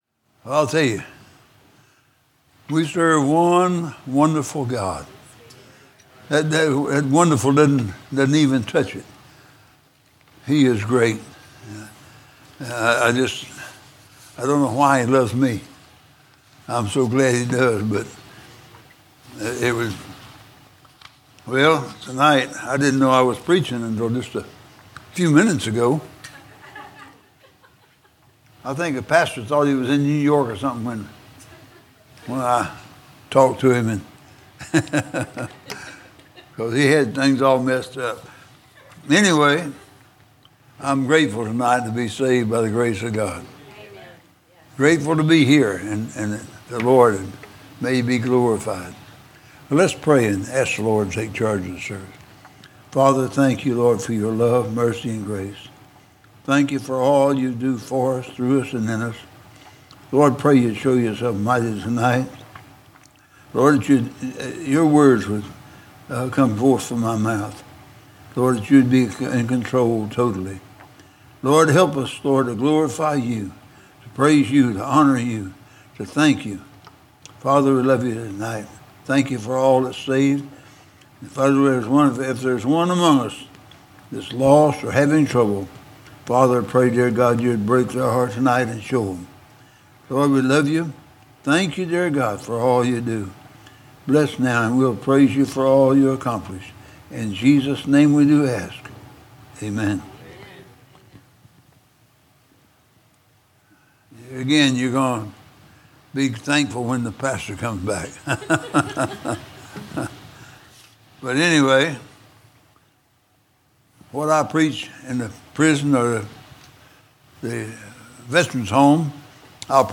Sermons not part of a specific series